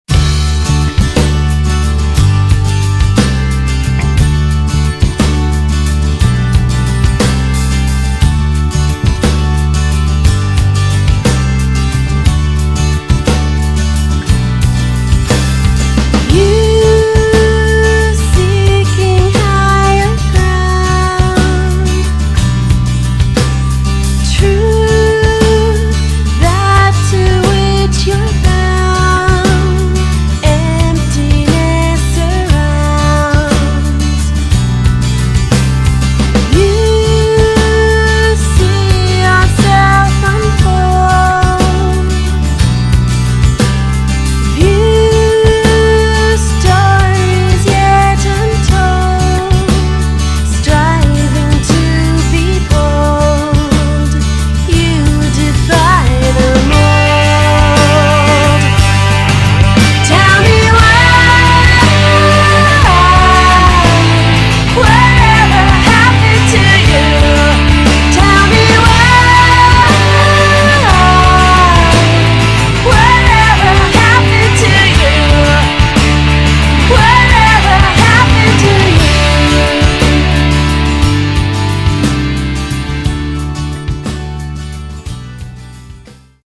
on drums
bass
percussion